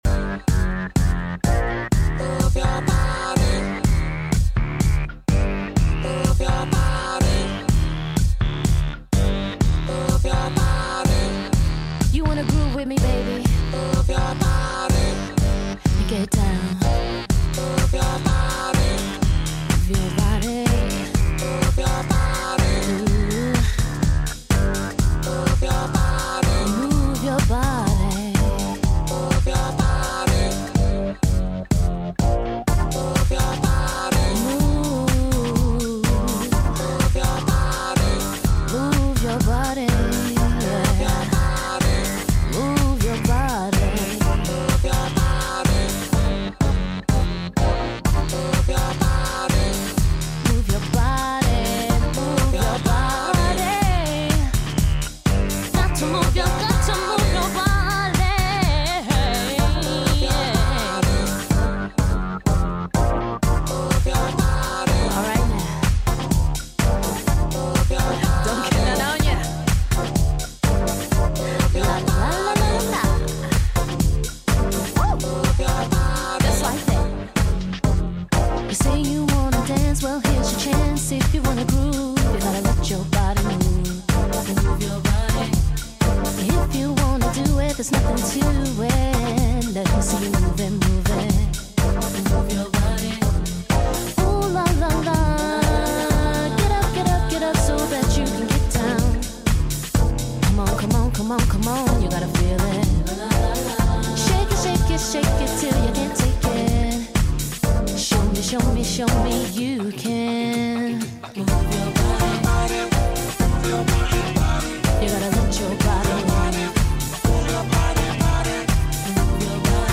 Ce petit rituel portant sur une seule émission est faite en improvisation, en one-shot à la one again.